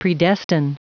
Prononciation du mot predestine en anglais (fichier audio)
Prononciation du mot : predestine